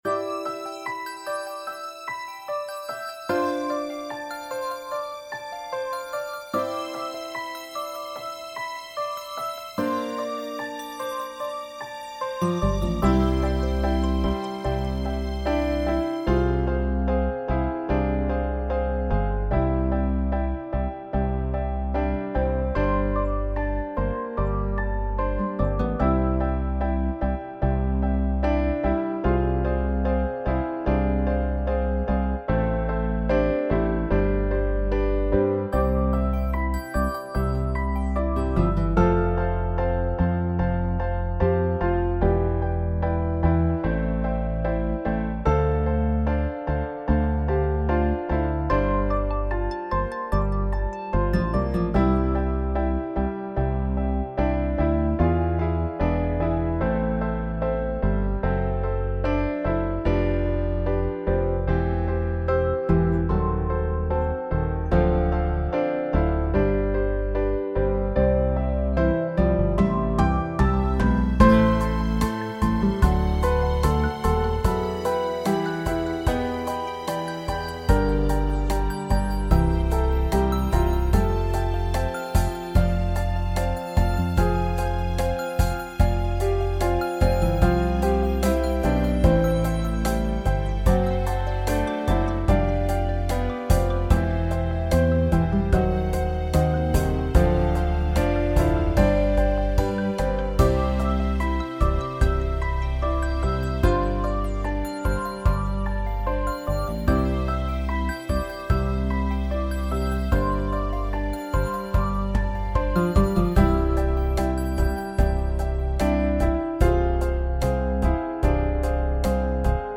Stars - Backing Track